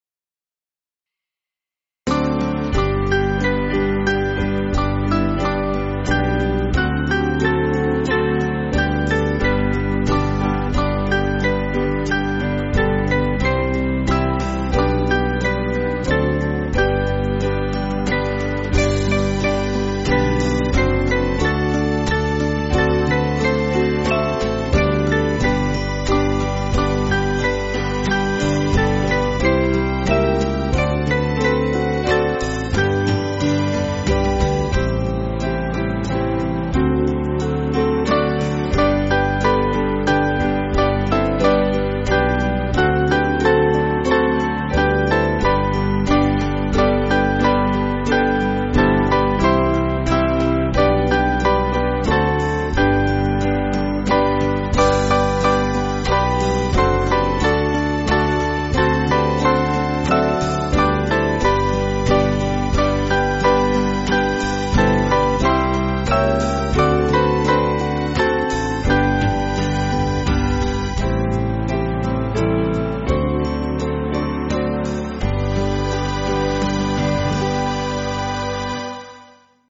Small Band
(CM)   2/G